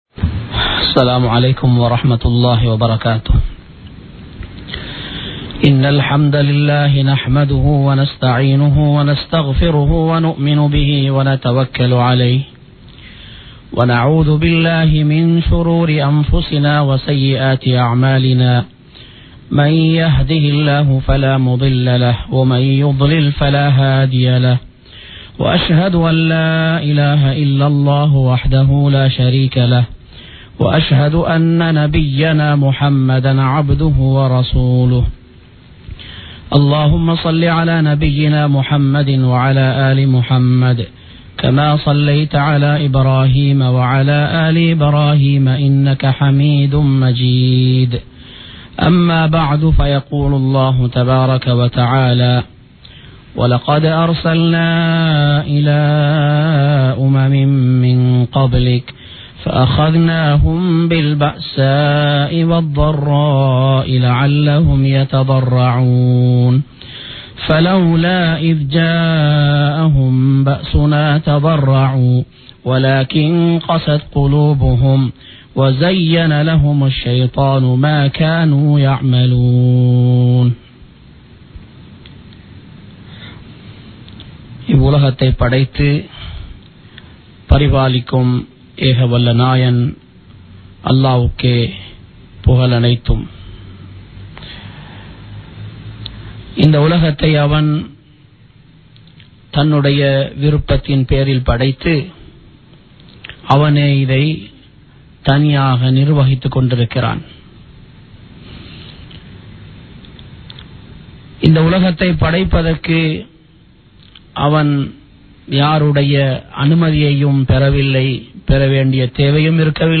சோதனைகள் ஏன் ஏற்படுகின்றன? | Audio Bayans | All Ceylon Muslim Youth Community | Addalaichenai
Colombo 03, Kollupitty Jumua Masjith